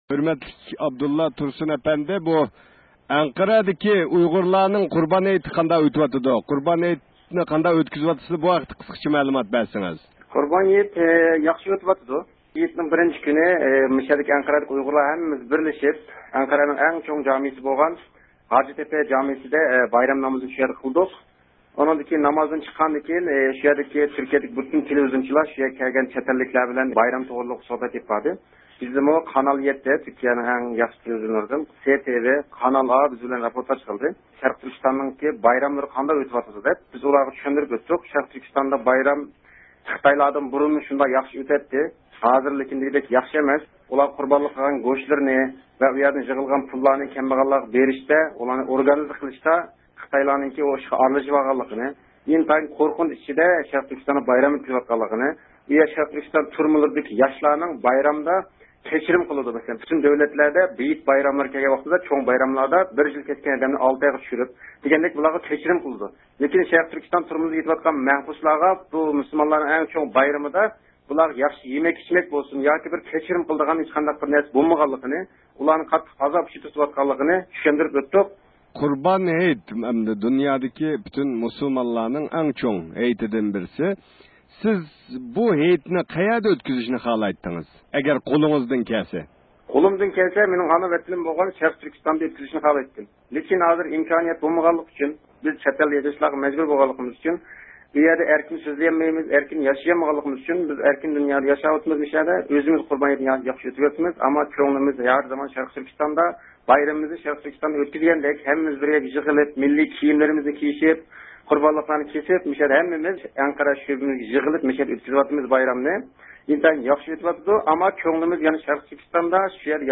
بىز تۈركىيىدىكى ئۇيغۇرلار زىچ ئولتۇراقلاشقان شەھەرلەردىن ئىستانبۇل، قەيسىرى ۋە ئەنقەرەدىكى ئۇيغۇرلارغا تېلېفون قىلىپ ھېيتنى قانداق ئۆتكۈزۈۋاتقانلىقىنى سورىدۇق.